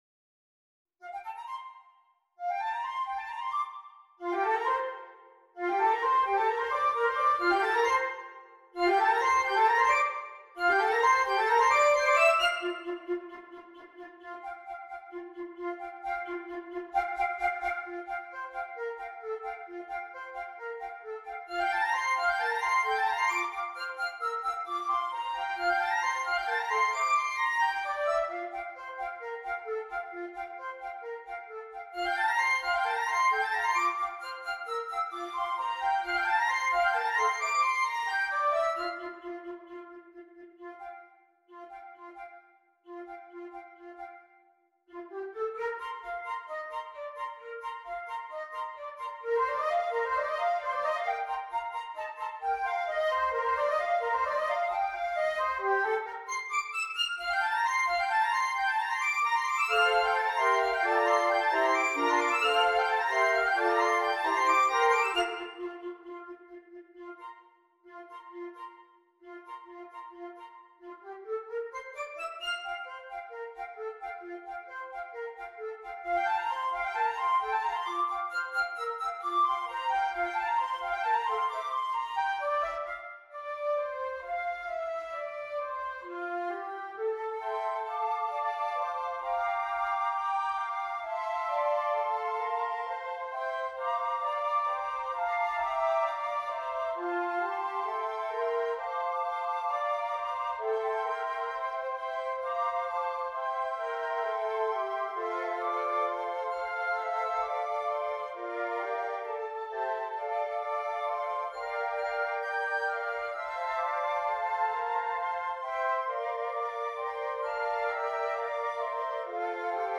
6 Flutes